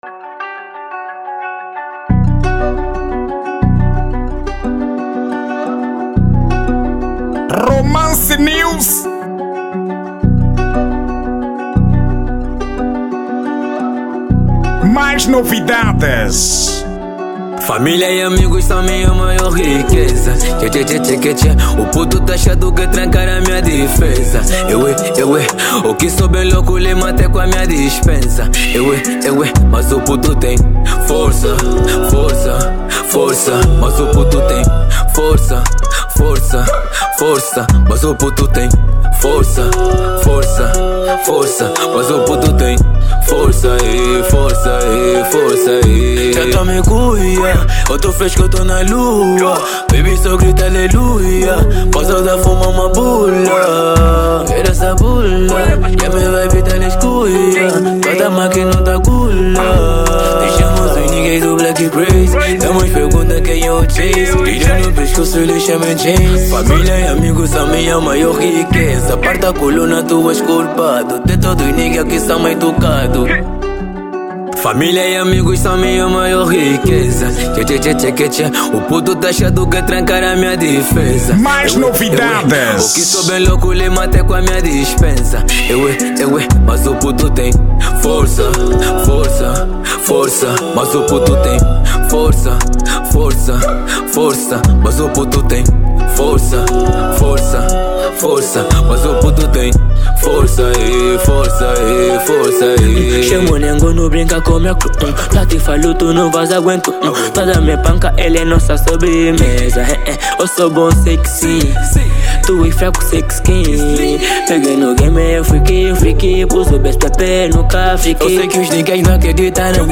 Estilo: Rap Trap